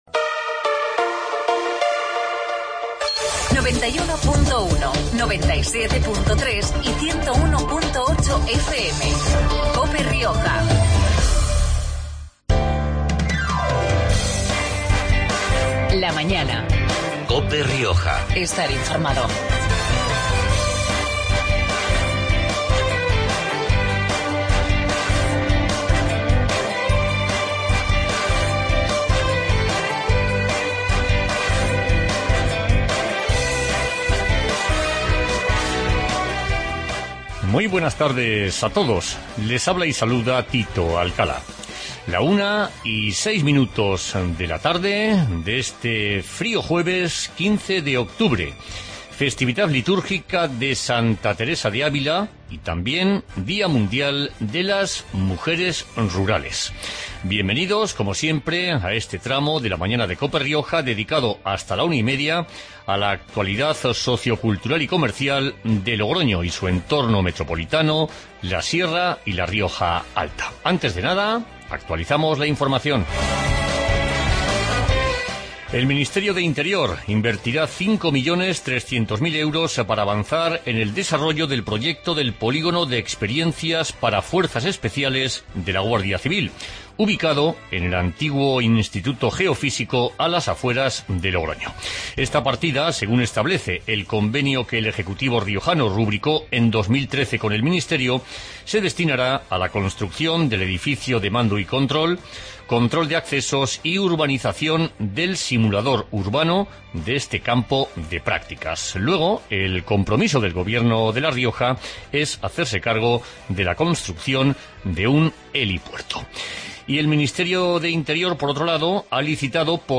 AUDIO: Programa regional de actualidad, entrevistas y entretenimiento.